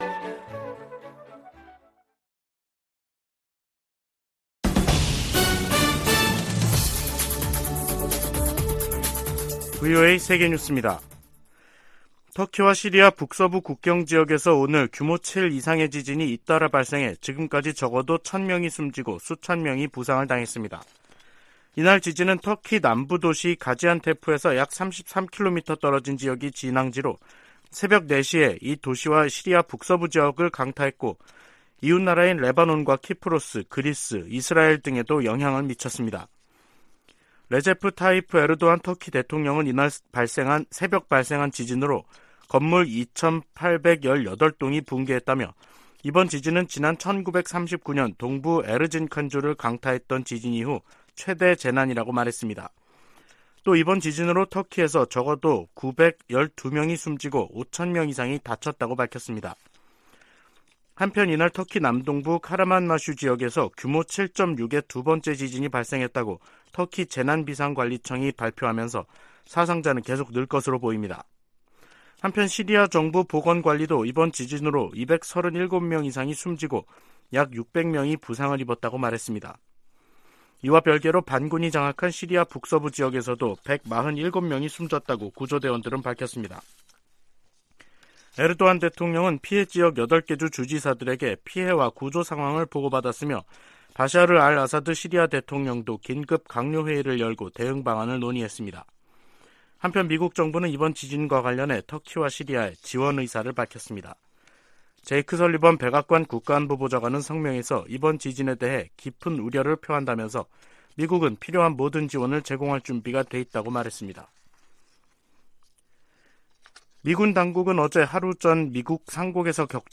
VOA 한국어 간판 뉴스 프로그램 '뉴스 투데이', 2023년 2월 6일 2부 방송입니다. 워싱턴에서 열린 미한 외교장관 회담에서 토니 블링컨 미 국무장관은, ‘미국은 모든 역량을 동원해 한국 방어에 전념하고 있다’고 말했습니다. 미국과 중국의 ‘정찰 풍선’ 문제로 대립 격화 가능성이 제기되고 있는 가운데, 북한 문제에 두 나라의 협력 모색이 힘들어질 것으로 전문가들이 내다보고 있습니다.